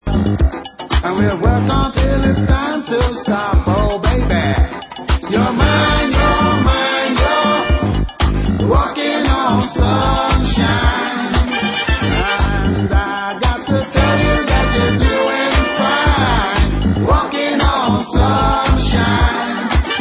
World/Reggae